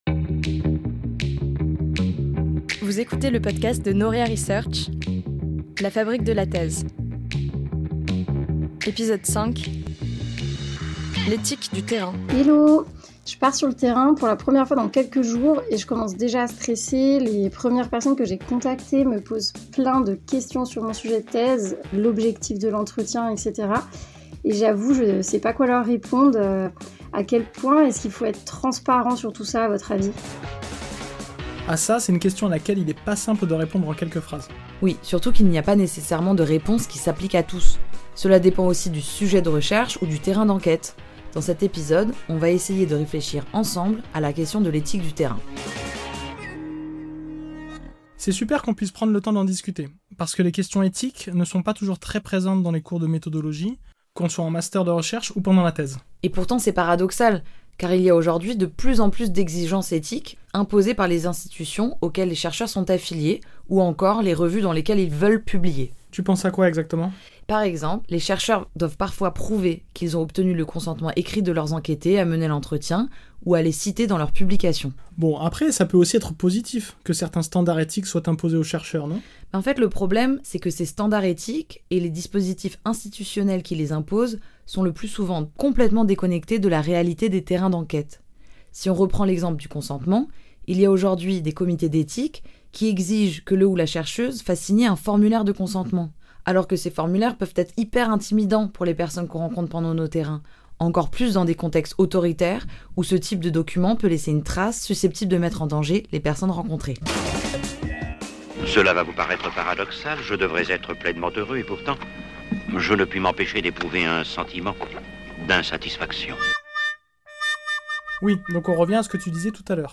Entretien
À travers des témoignages de chercheur·ses, l’épisode explore les arbitrages et décisions éthiques que chaque chercheur·se doit faire en fonction du contexte et des risques (anonymisation des données, rétribution des enquêté·es, sécurisation des informations, positionnement face aux acteurs locaux...).